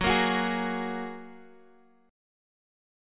F#add9 Chord